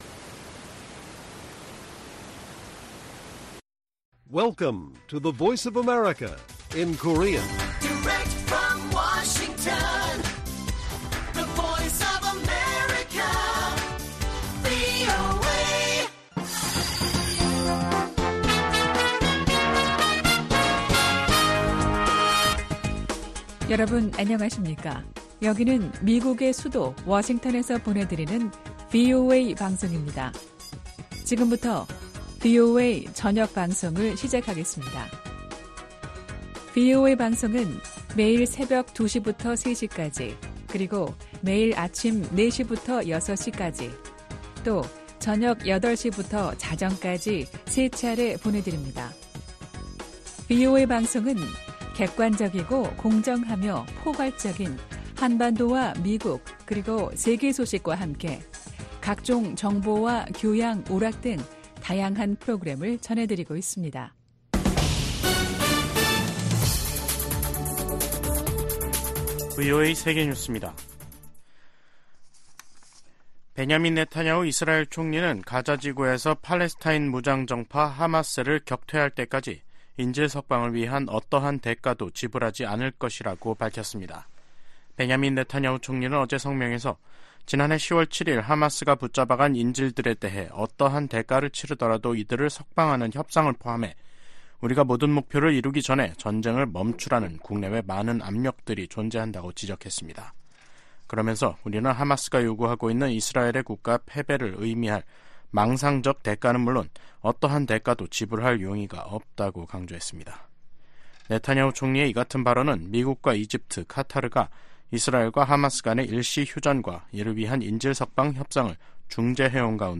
VOA 한국어 간판 뉴스 프로그램 '뉴스 투데이', 2024년 2월 21일 1부 방송입니다. 러시아가 우크라이나 공격에 추가로 북한 미사일을 사용할 것으로 예상한다고 백악관이 밝혔습니다. 미국 정부는 북일 정상회담 가능성에 역내 안정에 기여한다면 환영할 일이라고 밝혔습니다.